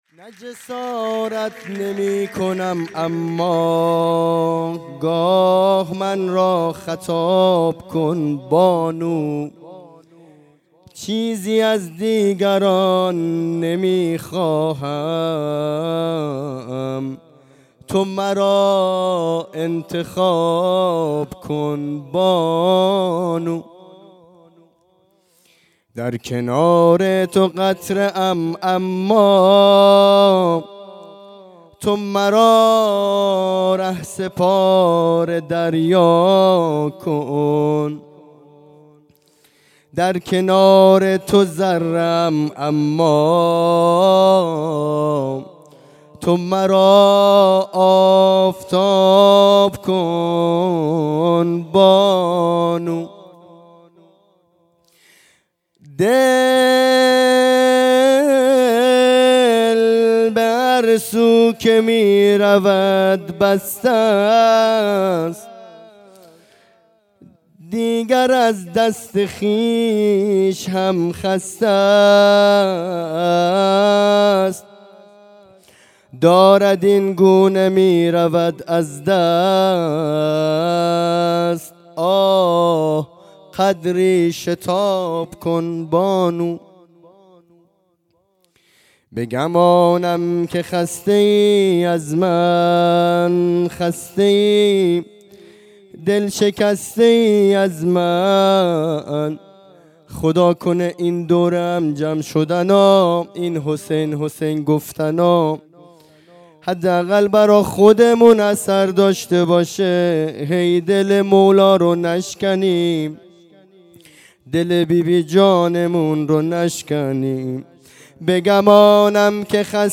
جلسۀ هفتگی (به مناسبت ولادت حضرت معصومه(س))